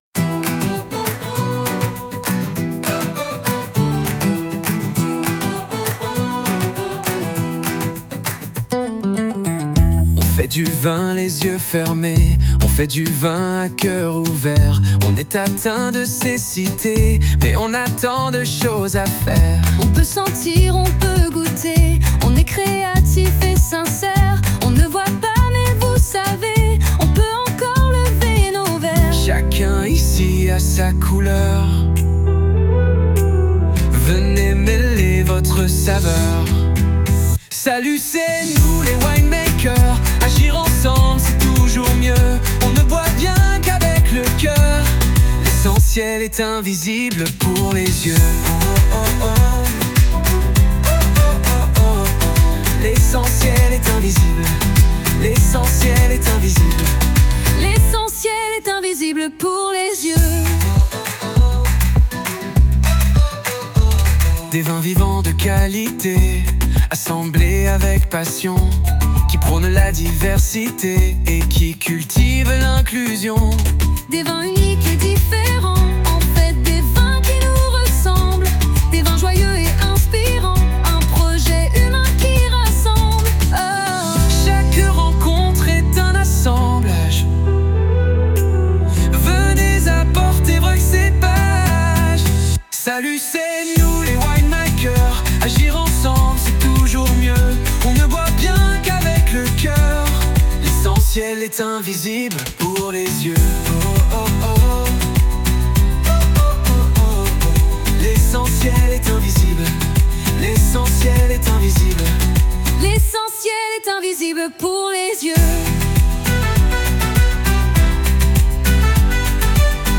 La musique de la vidéo est une chanson, une sorte d’hymne, dont j’ai écrit les paroles et fait composer et jouer la musique à une intelligence artificielle, Suno. Elle résume très bien le projet et permet de facilement le raconter.